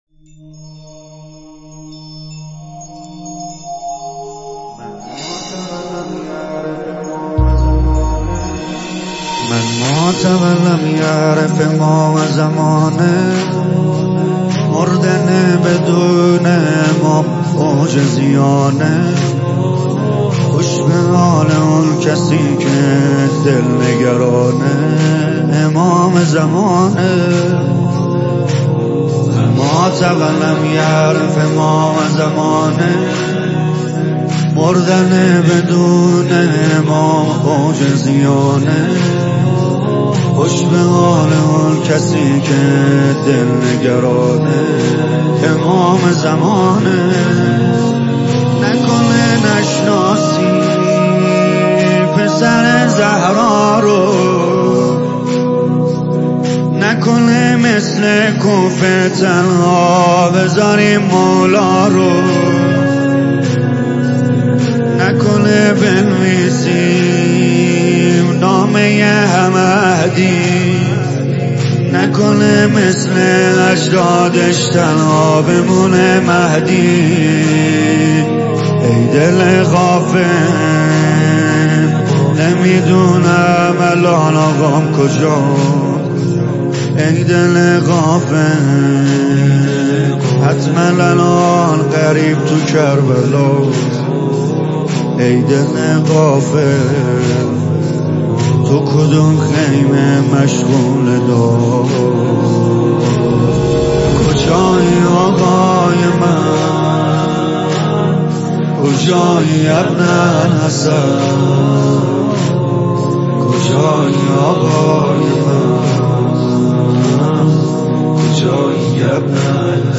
مداحی
هیئت هفتگی
نوحه